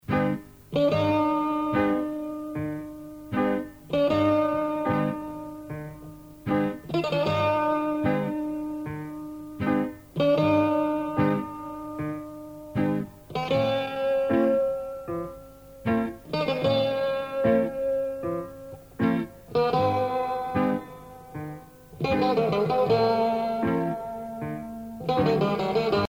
danse : sirtaki
Pièce musicale éditée